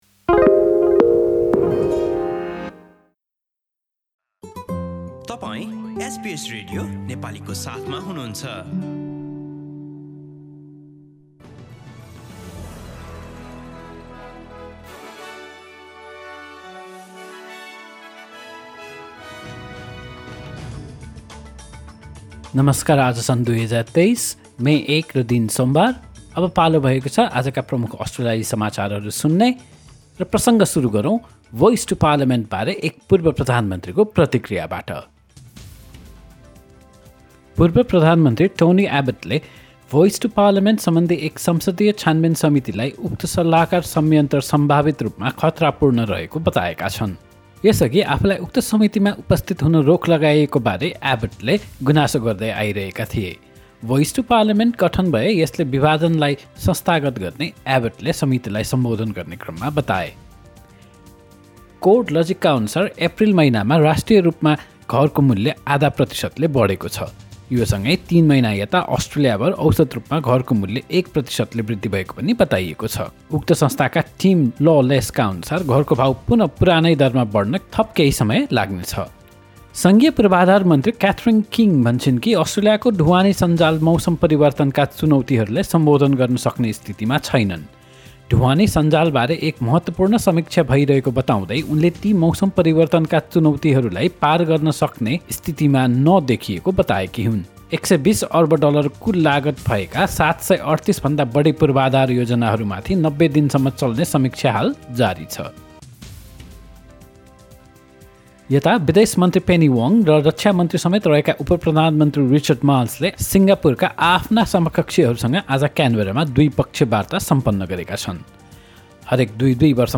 एसबीएस नेपाली प्रमुख अस्ट्रेलियाली समाचार : सोमवार, १ मे २०२३